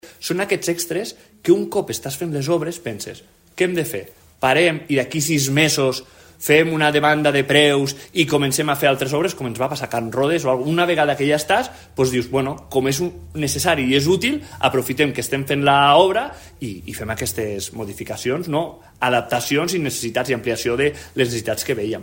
En resposta, des de la majoria, el cònsol major, Sergi González, ha defensat que els increments responen a modificacions necessàries durant l’execució de l’obra, especialment en matèria d’accessibilitat universal.